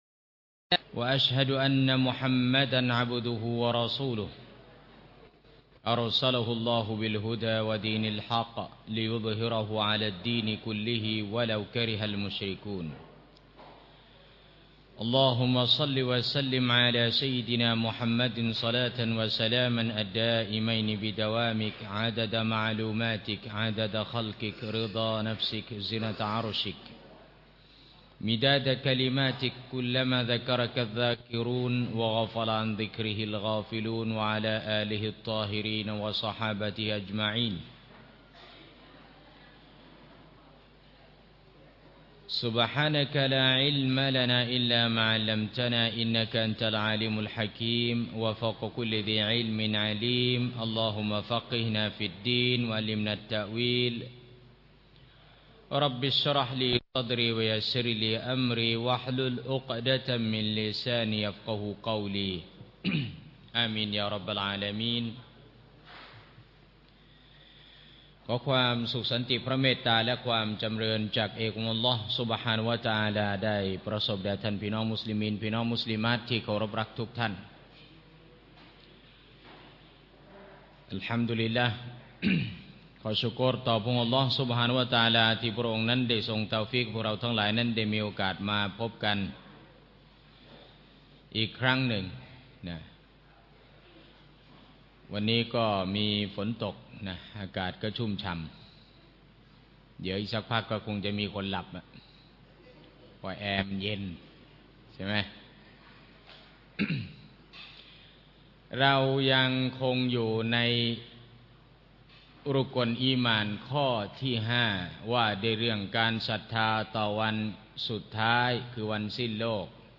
บรรยาย ณ มัสญิดบางปลา จ.สมุทรปราการ (ทุกวันอาทิตย์ที่ 2 ของเดือน)